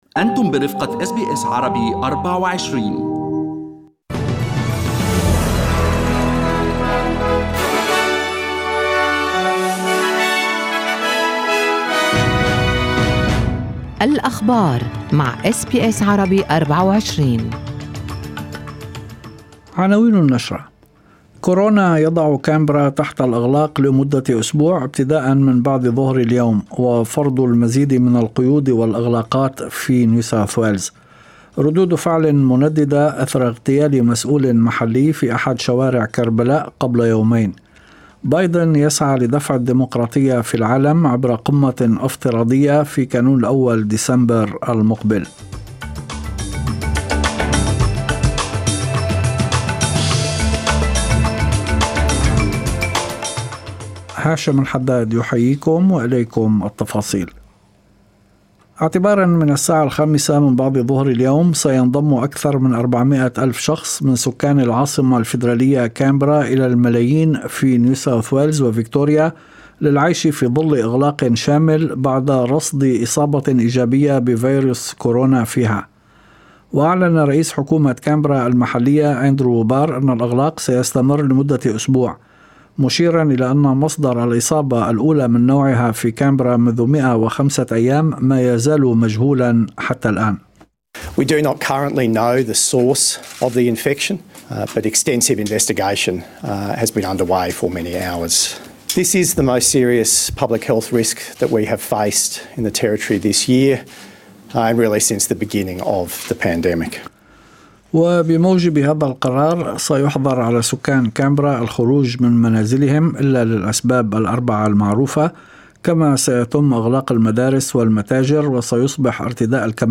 نشرة أخبار المساء 12/8/2021
يمكنكم الاستماع الى النشرة الاخبارية كاملة بالضغط على التسجيل الصوتي أعلاه.